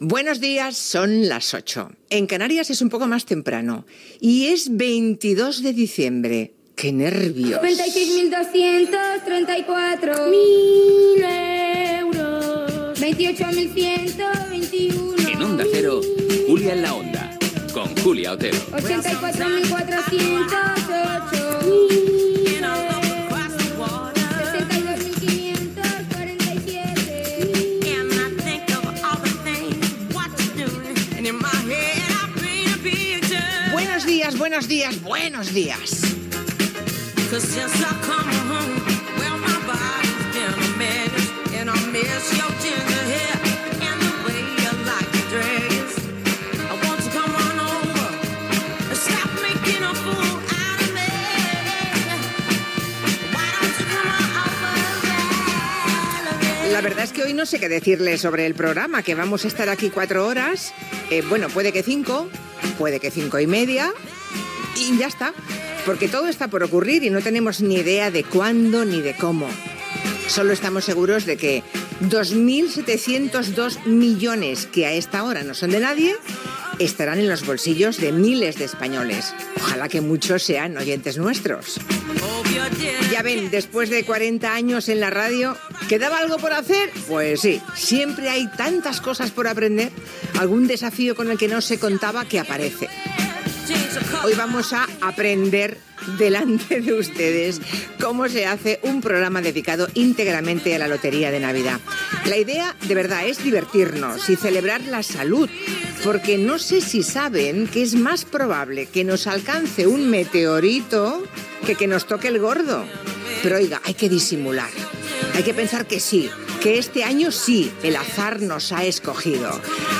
Hora, data, presentació del programa especial dedicat a la rifa de Nadal. Connexió amb el Teatro Real
Entreteniment